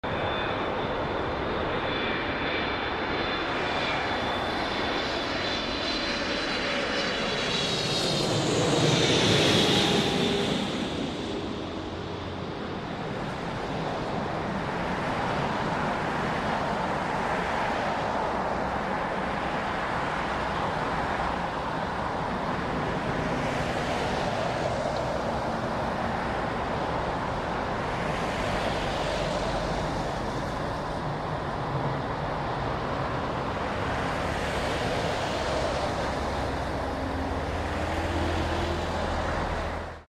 Gorgeous Boeing 777-300 Landing Heathrow